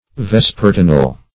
Vespertinal \Ves"per*ti`nal\, a.